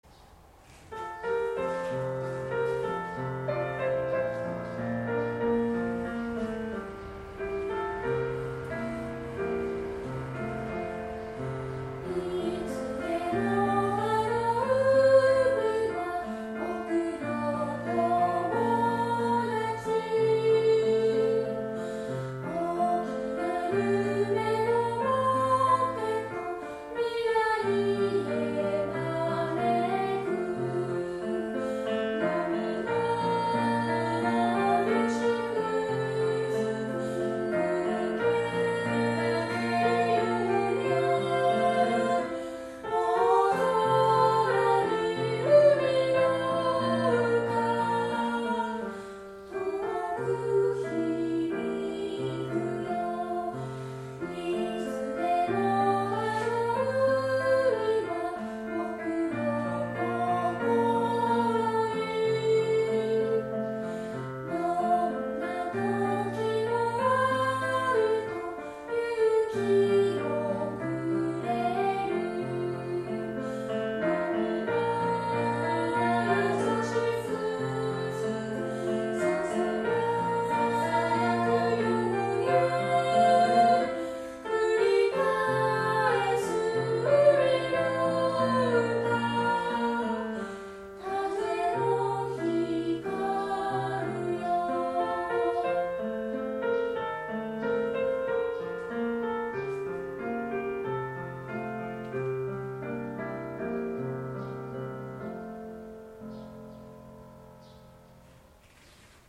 合唱